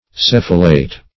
cephalate - definition of cephalate - synonyms, pronunciation, spelling from Free Dictionary Search Result for " cephalate" : The Collaborative International Dictionary of English v.0.48: Cephalate \Ceph"a*late\, a. (Zool.)